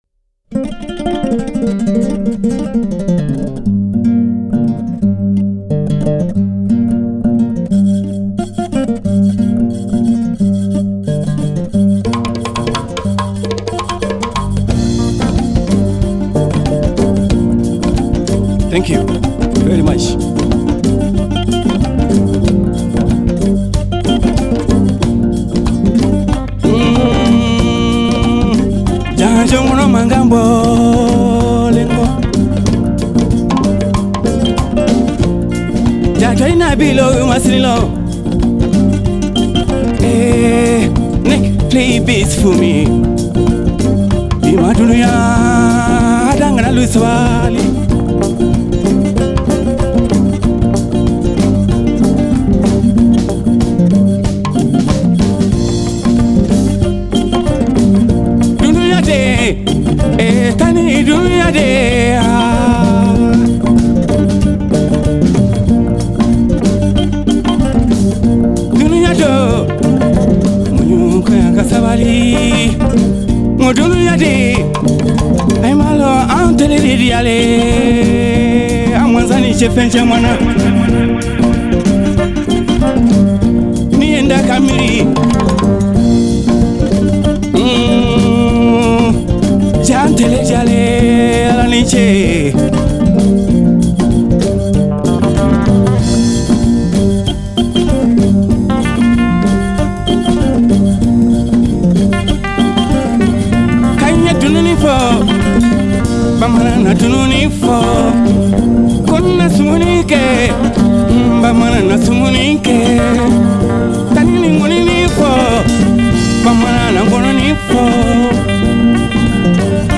jembe
drumset
The funk is rounded out by myself on bass
keyboards